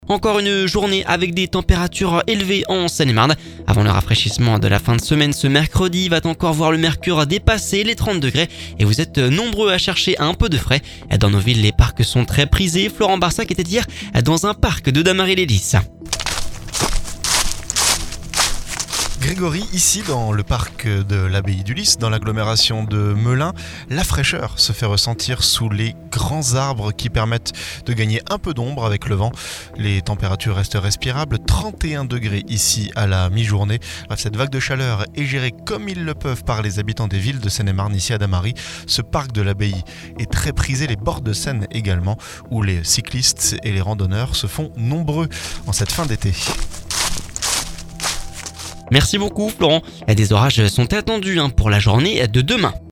dans un parc de Dammarie les Lys.